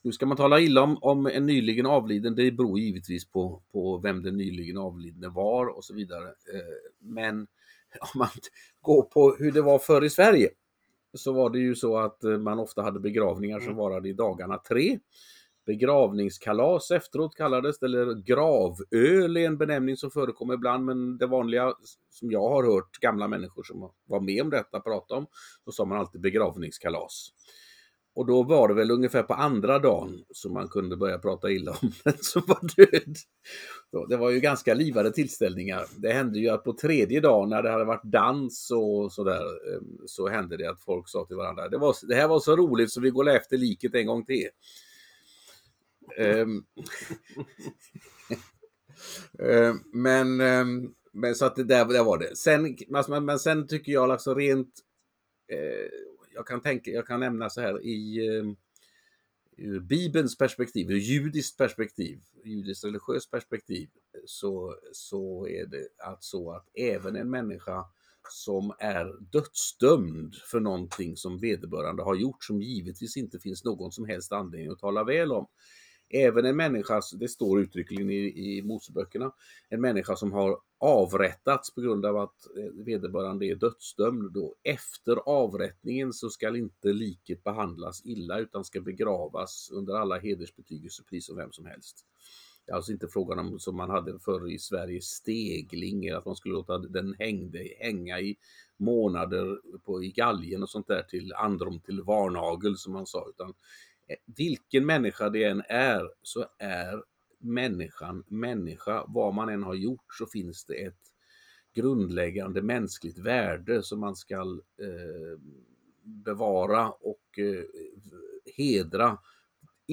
PODCAST Den amerikanska radioprataren Rush Limbaugh avled på torsdagen i lungcancer.